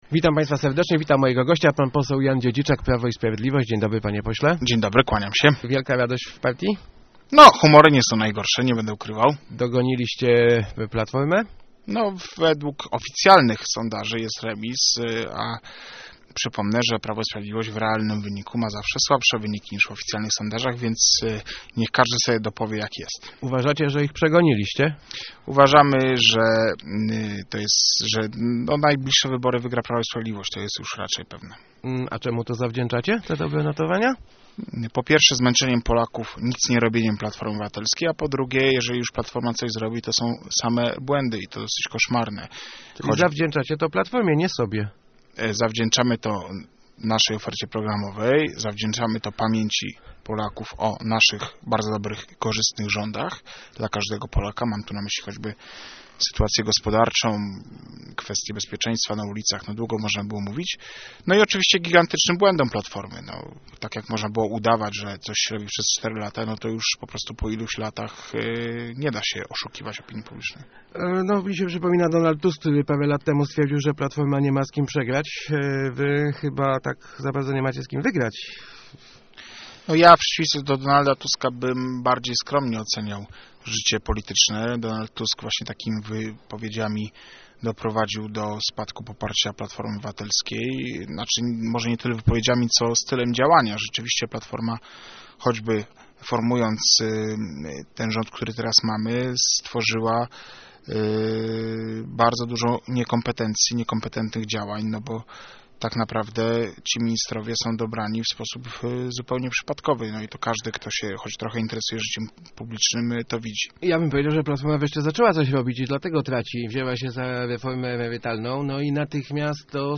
Pa�stwo powinno wspiera� posiadanie dzieci - mówi� w Rozmowach Elki pose� PiS Jan Dziedziczak. Zapewnia on, �e PiS ma systemowe rozwi�zania maj�ce poprawi� sytuacj� demograficzn� - na przyk�ad zerow� stawk� VAT na ubranka i artku�y dzieci�ce.